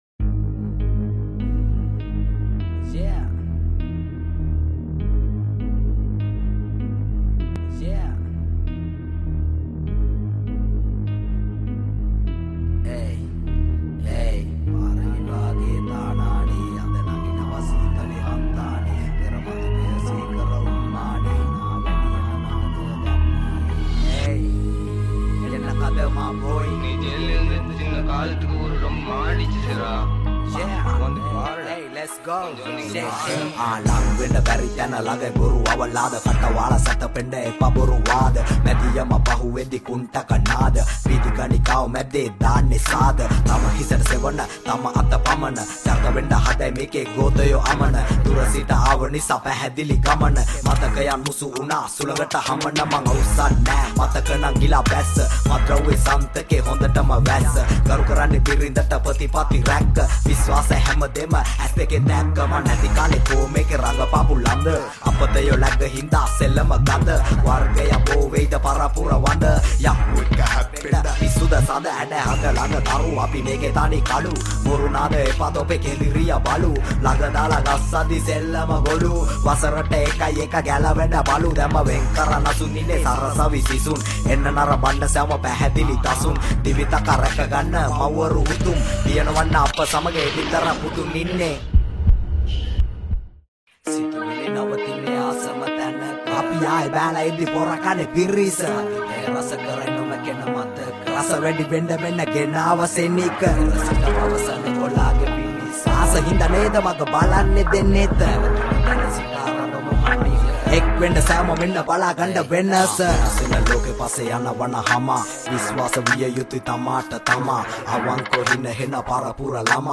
sinhala rap